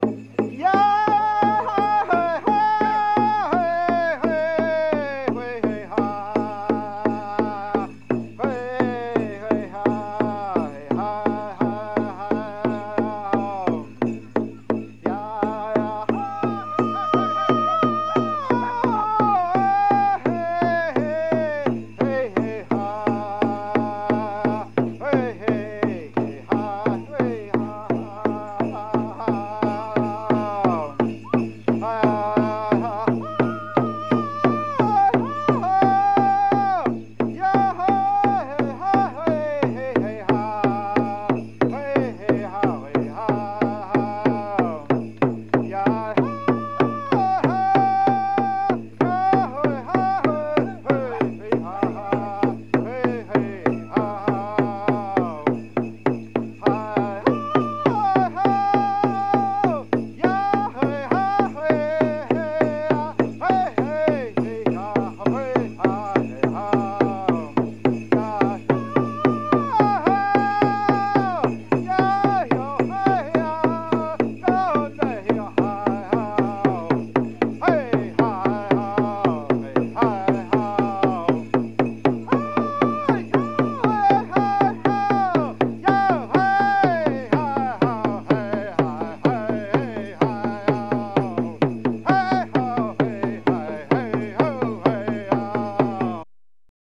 Ритуальные песни с бубнами у североамериканских индейцев